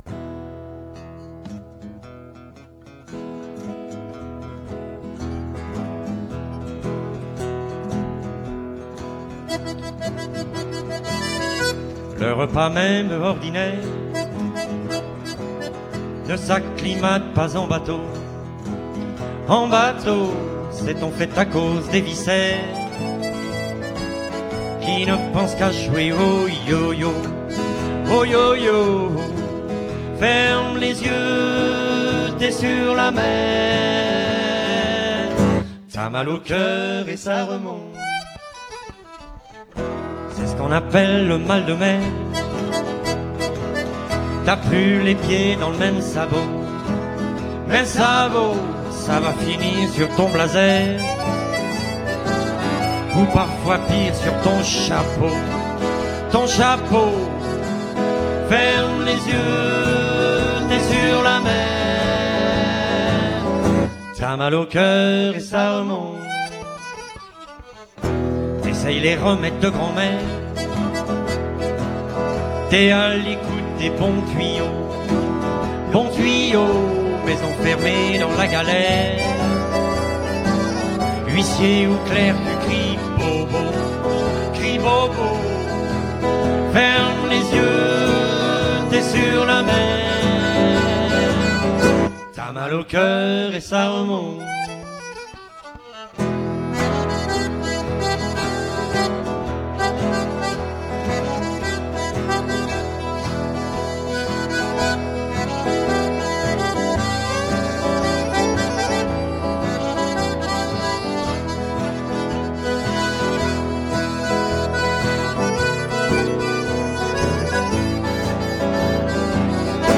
extrait d'un concert donné à St Malo